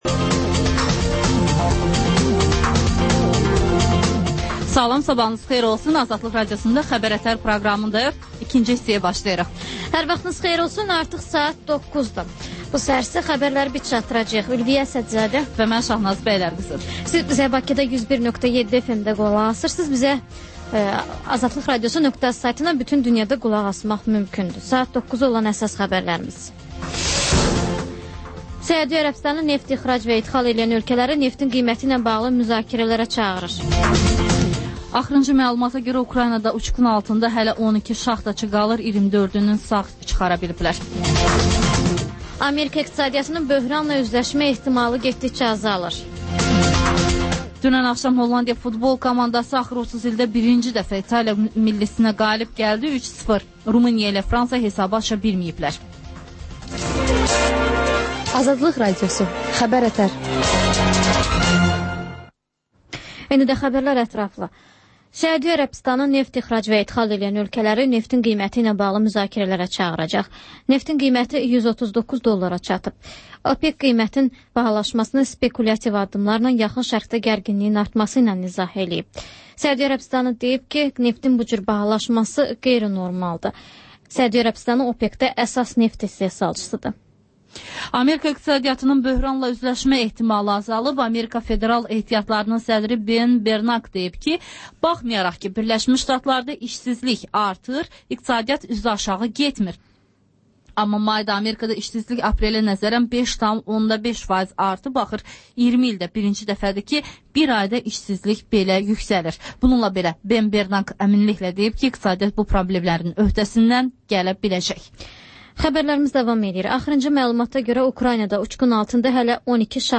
Xəbər-ətər: xəbərlər, müsahibələr, sonra İZ mədəniyyət proqramı